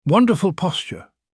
narrator-david-attenburo - David Attenborough narrates your life